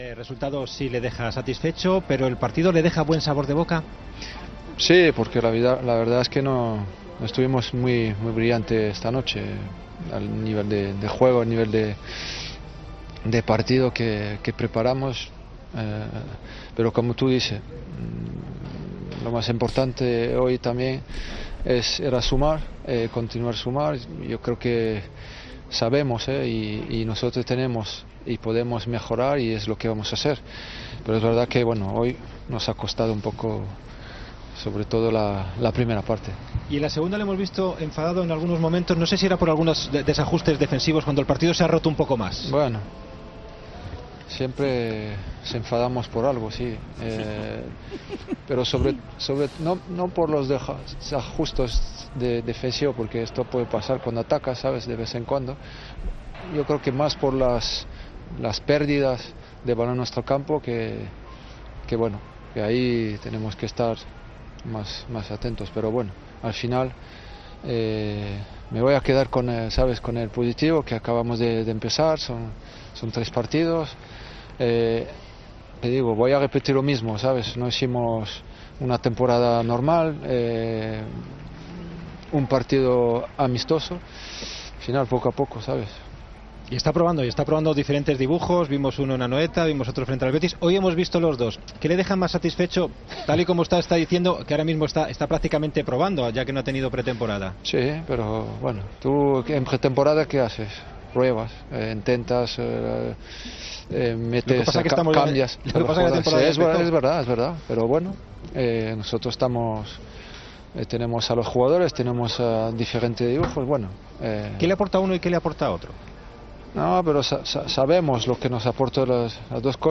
"No puedo decir cuanto tiempo estará de baja, se ha hecho un poco daño en el entrenamiento antes del partido, era como una contractura y al final ha sido un poco más. Es a nivel muscular, no tiene nada que ver con su lesión de tobillo. Podía pasar porque lleva tiempo sin jugar pero creo que no es mucho", dijo en rueda de prensa.